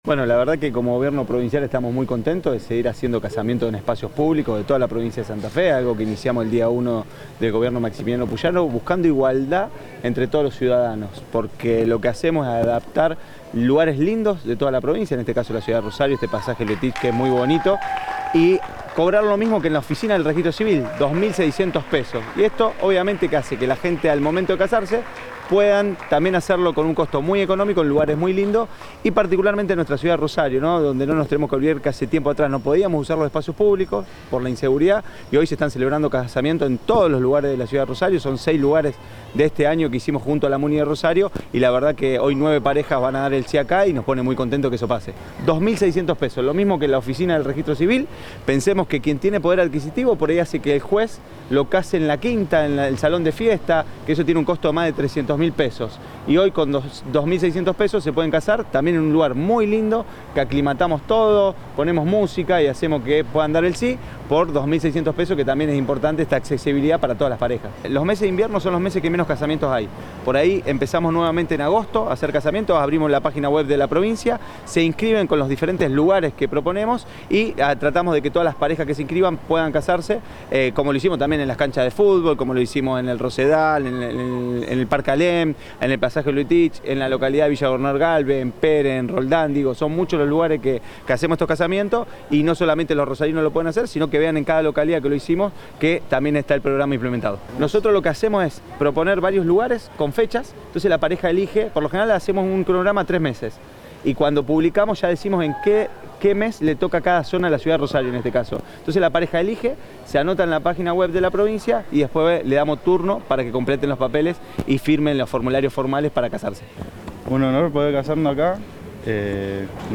Matías Figueroa Escauriza, secretario de Gestión de Registros Provinciales